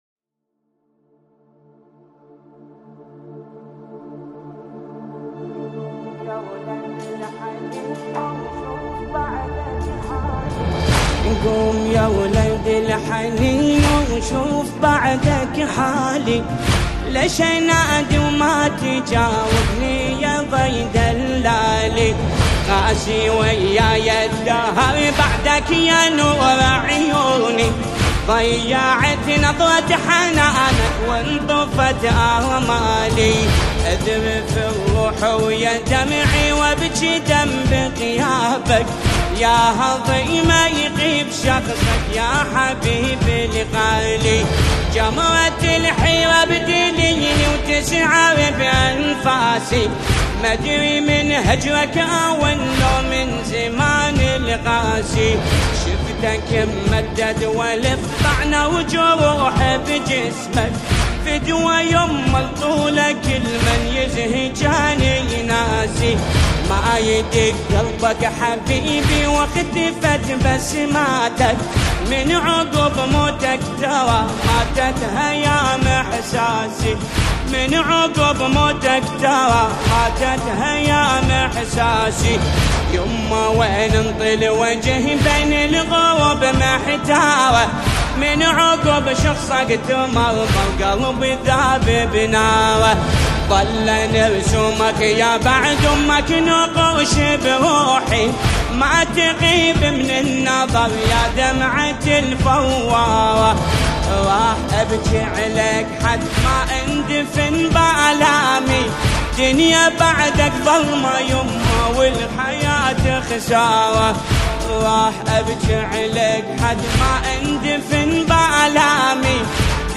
نماهنگ دلنشین عربی
الرادود